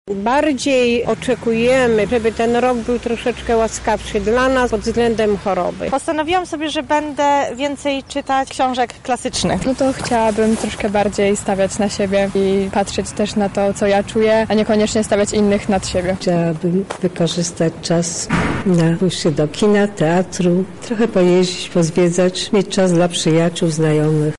Ze względu na początek 2023 roku zapytaliśmy mieszkańców Lublina jakie cele wyznaczyli sobie na najbliższy rok.
SONDA